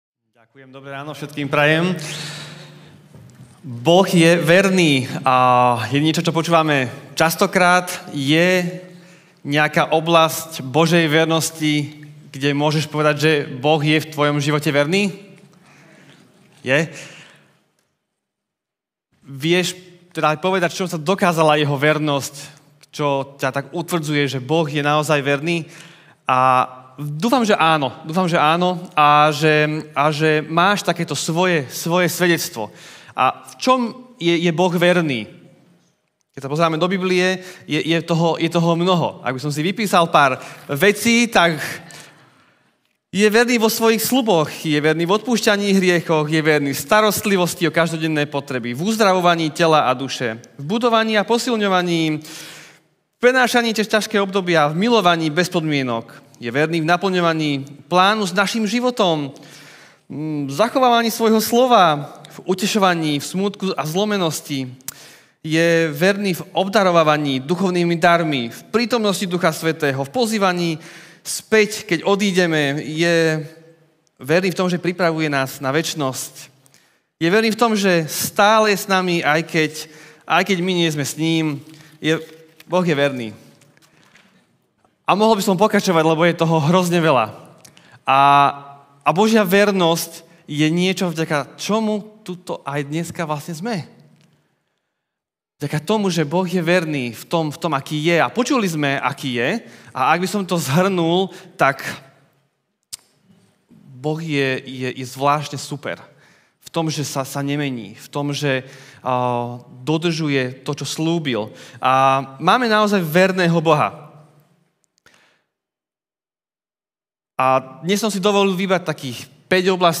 Boh je verný Kázeň týždňa Zo série kázní